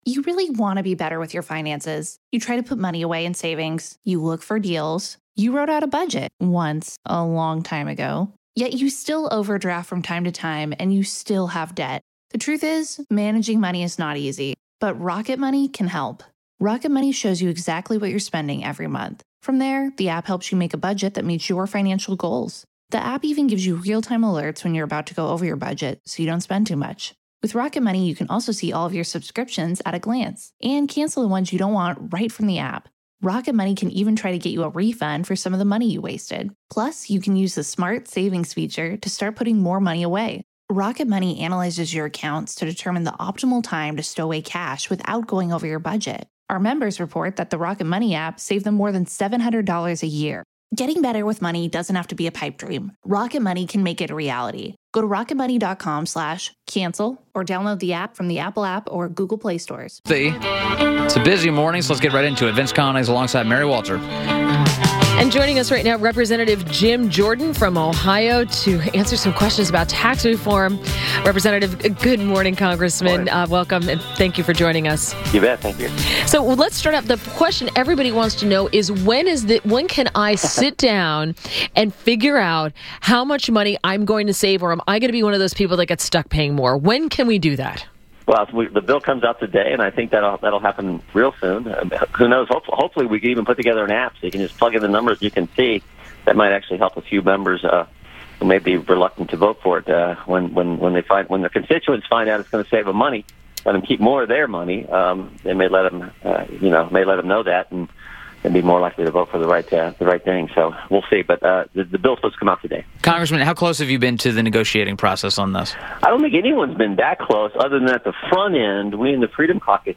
WMAL Interview - REP.